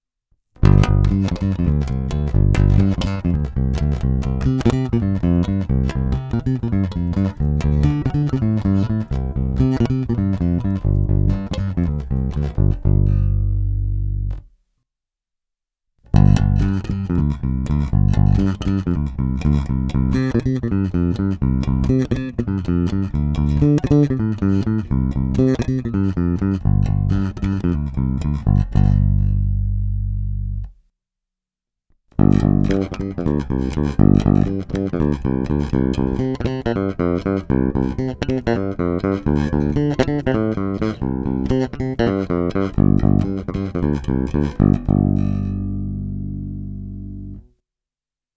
Není-li uvedeno jinak, jsou provedeny rovnou do zvukové karty s korekcemi ve střední poloze, dále jen normalizovány, jinak ponechány bez postprocesingu.